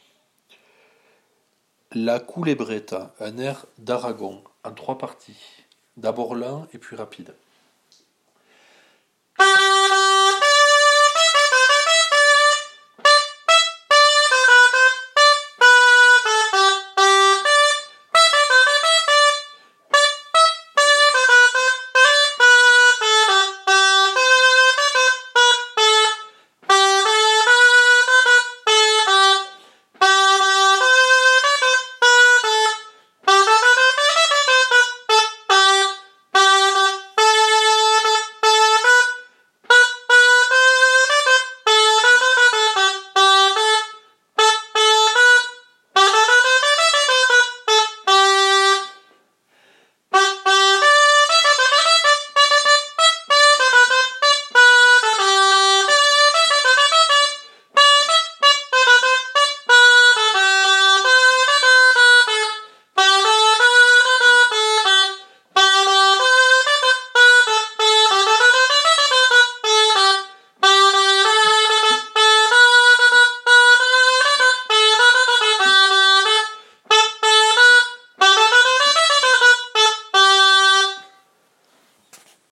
air d’Aragon, en 3 parties